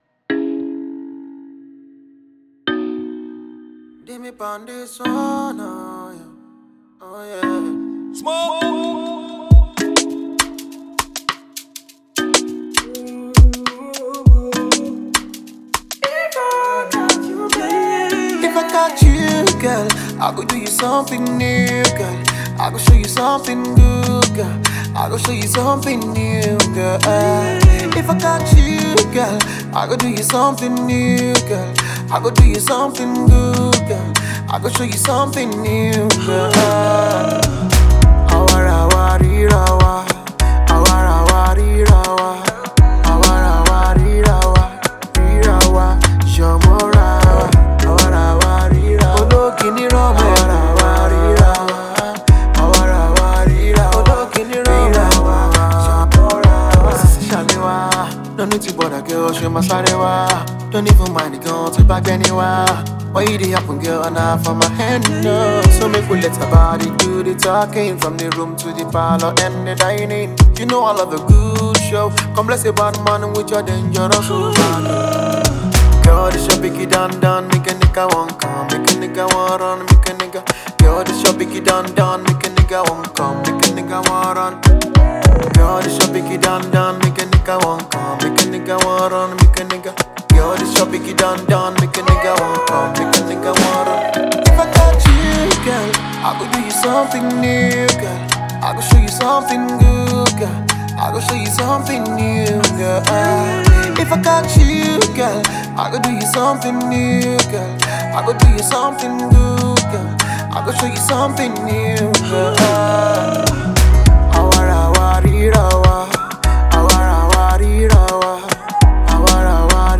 Nigerian Afro-Pop singer
The song has a positive vibe.
distinctive artiste with serenading vocal prowess
soft loveable tune